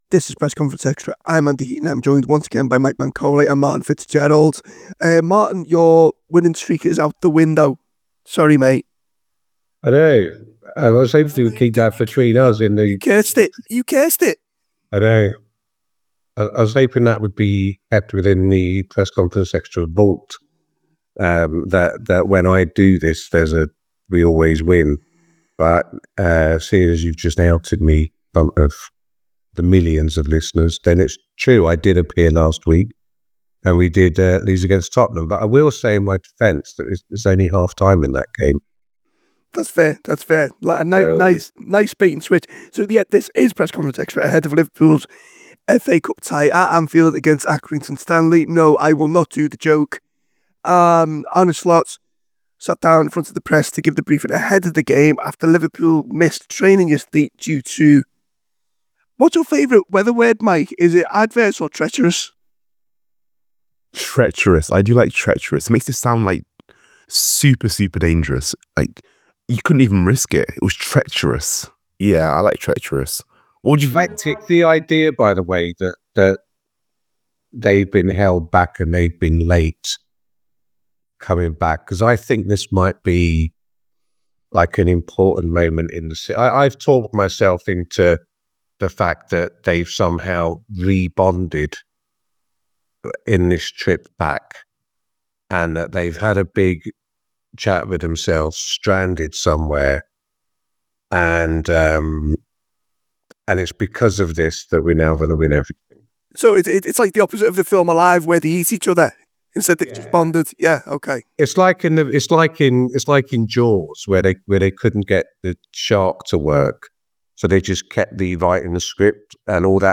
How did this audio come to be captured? Below is a clip from the show – subscribe for more on the Liverpool v Accrington Stanley press conference…